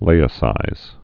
(lāĭ-sīz)